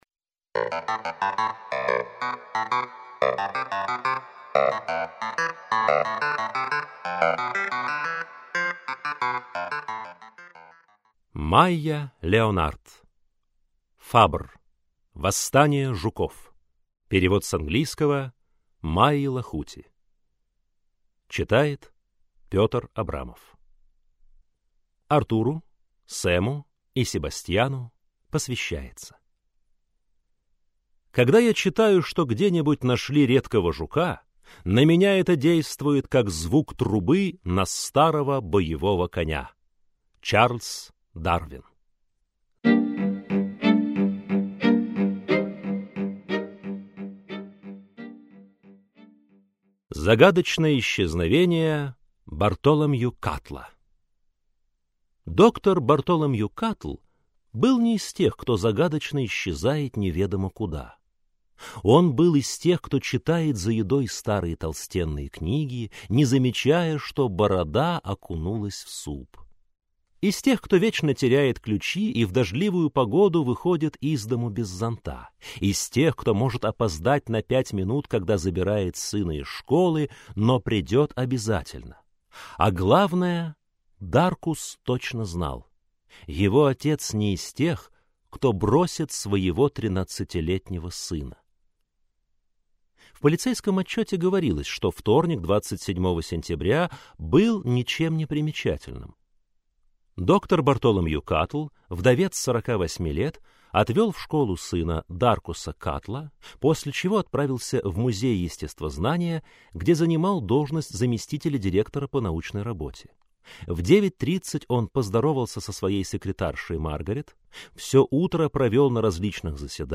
Аудиокнига Фабр. Восстание жуков | Библиотека аудиокниг